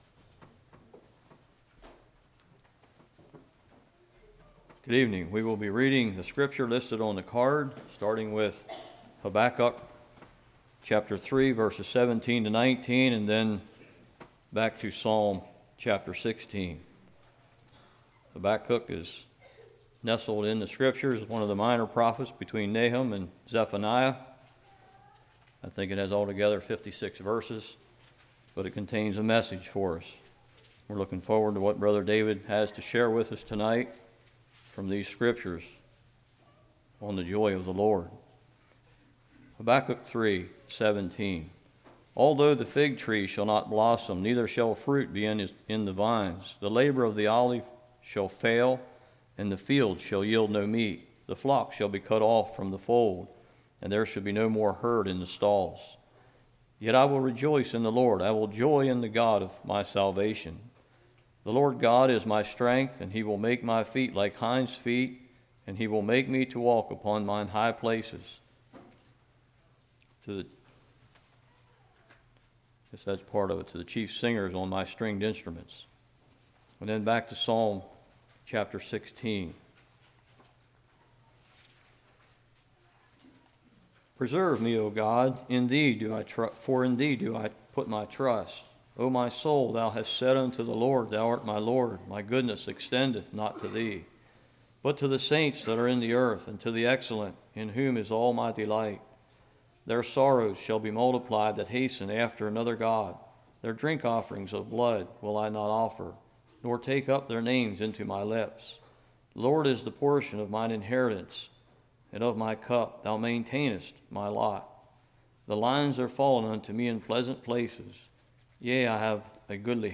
Passage: Hebrews 3:17-19, Psalms 16 Service Type: Revival